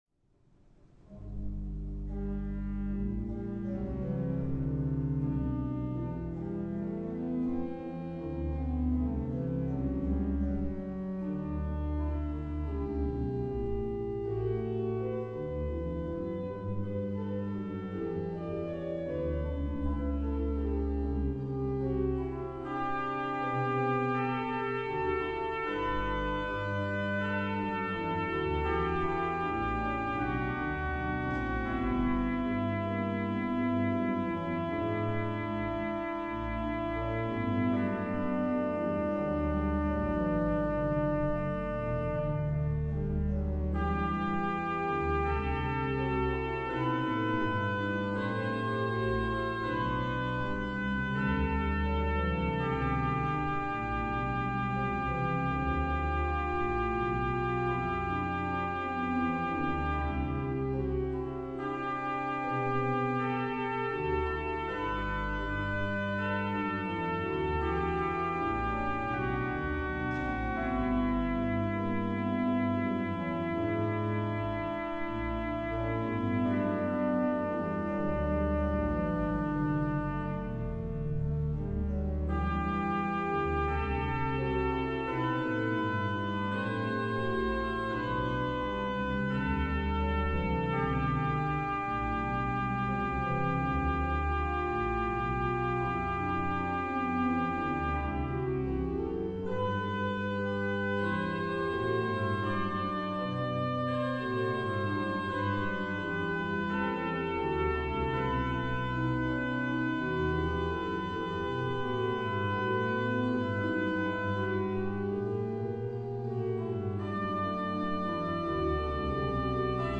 Tuttlingen, Stadtkirche, MÖNCH&PRACHTEL-Orgel
Celesta und Röhrenglocken.
Choralvorspiel aus op. 32